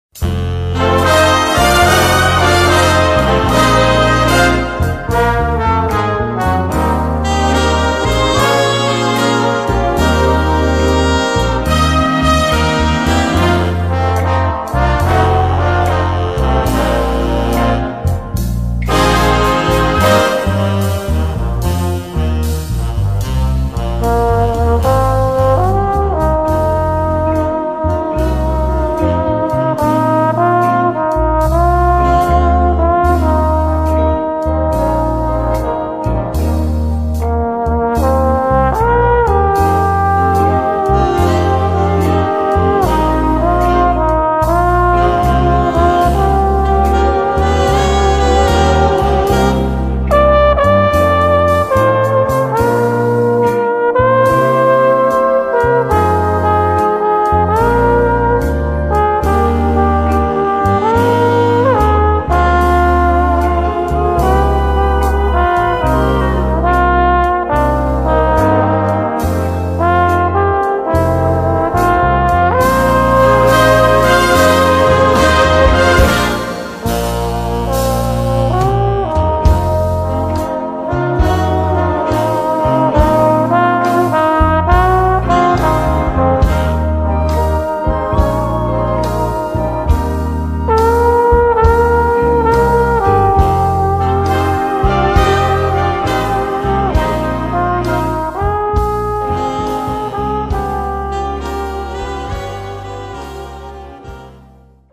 Voicing: Cornet and Brass Band